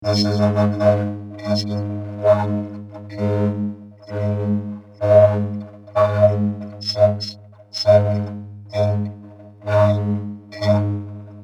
This is a vocoder I built.  I built it without ever having seen a vocoder.
vocoder.mp3